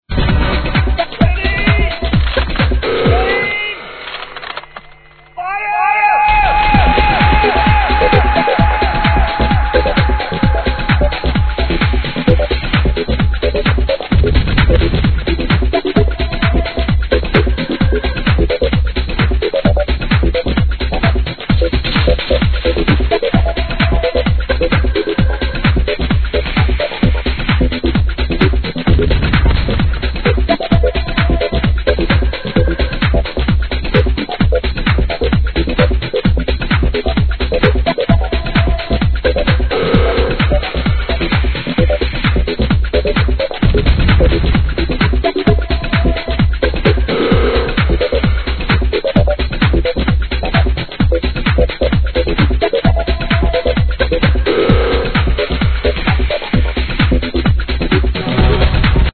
Drunk --- 2004 Prog House w/ vocal saying "ready aim fire"